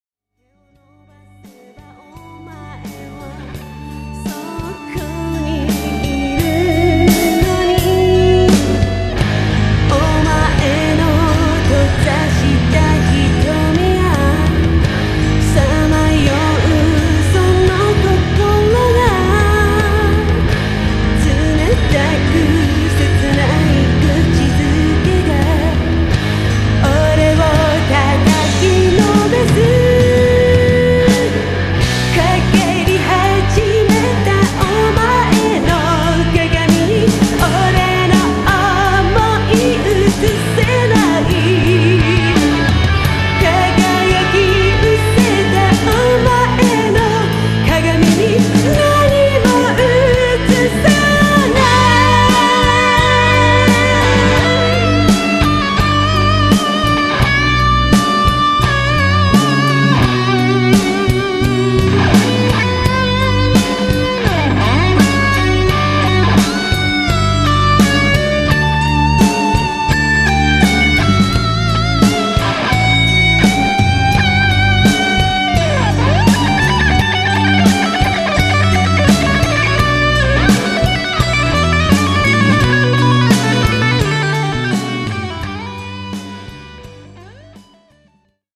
メロディアス・ハード